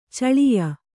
♪ caḷiya